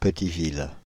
Petiville (French pronunciation: [pətivil]
Fr-Paris--Petiville.ogg.mp3